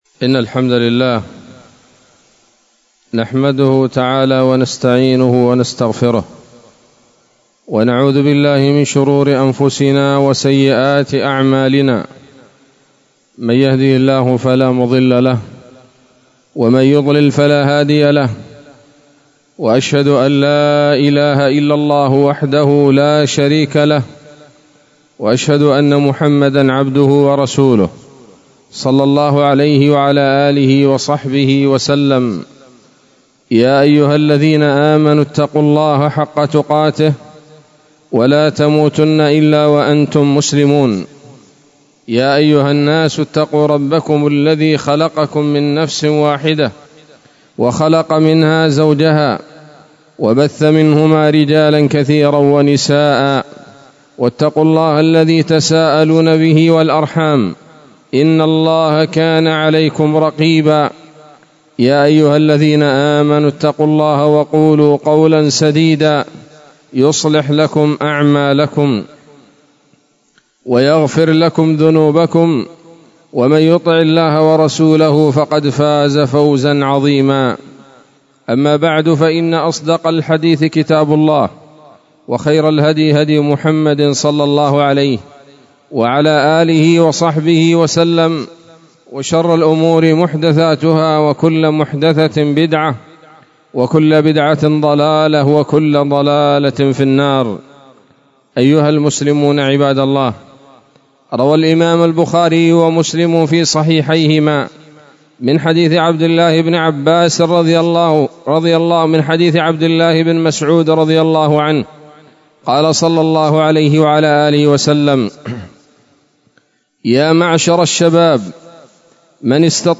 خطبة جمعة بعنوان: (( منكرات الأعراس )) 15 جمادى الأولى 1444 هـ، دار الحديث السلفية بصلاح الدين